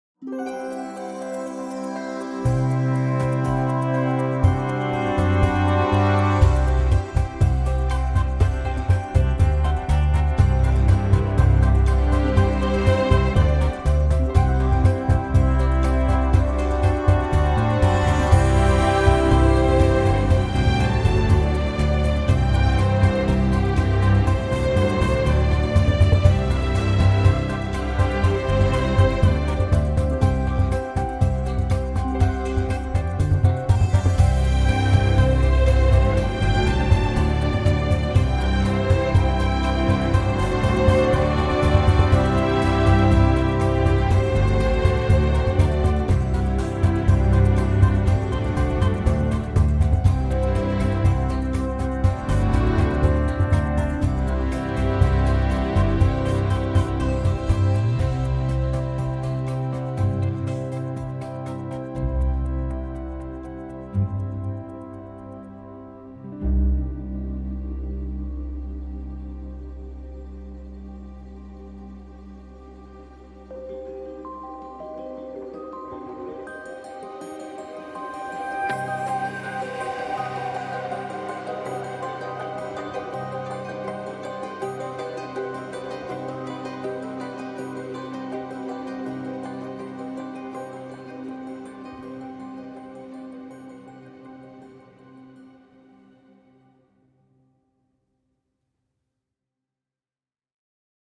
Les pizzicati ça va un peu